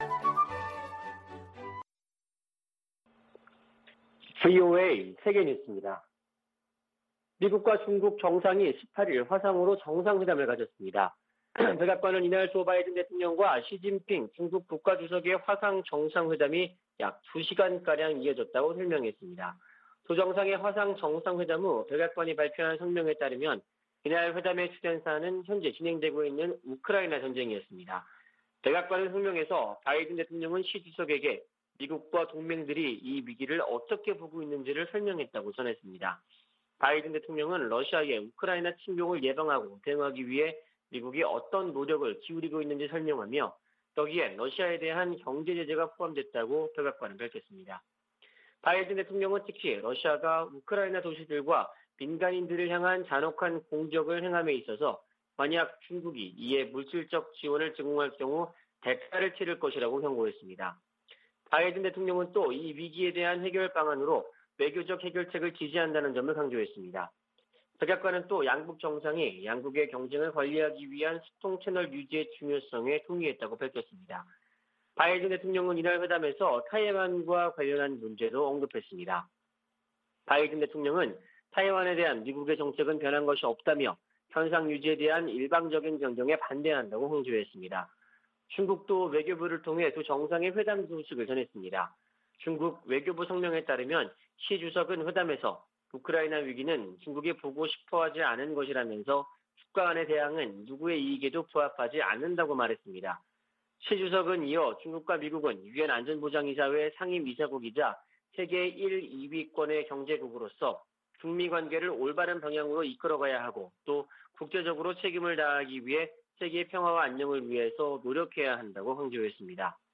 VOA 한국어 아침 뉴스 프로그램 '워싱턴 뉴스 광장' 2022년 3월 19일 방송입니다. 백악관은 북한의 미사일 발사를 거듭 규탄하고 한일 양국 방어 의지를 재확인했습니다. 한국의 윤석열 차기 정부가 현 정부보다 미국의 정책에 더 부합하는 외교정책을 추구할 것으로 미 의회조사국이 분석했습니다. 토마스 오헤아 퀸타나 유엔 북한인권특별보고관은 지난 6년 동안 북한 인권 상황이 더욱 악화했다고 평가했습니다.